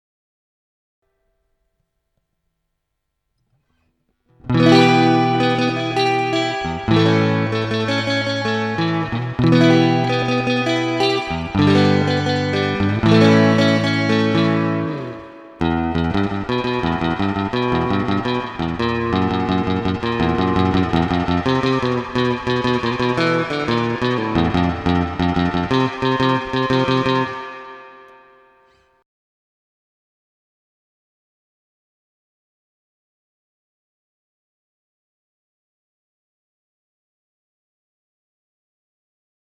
Ich habe die Höhen an der Klampfe voll aufgedreht und Volume auf 8-9. Ich habe mit allen Pickups-Stellungen direkt in das Interface eingespielt: "Blank" = reine Gitarre "Effekt" = Topanga Effekt vorgeschaltet (ähnlich wie im Video eingestellt) "Virtueller Amp" = SurfinStereo Preset in Logic X Wie beschrieben habe ich ein neues Pickguard mit 3 Single Coils, Pure Vintage 65er Fender Pickups drauf.
Das Geklimper ist ziemlich gestümpert, aber dürfte einen Eindruck geben, was ich meine.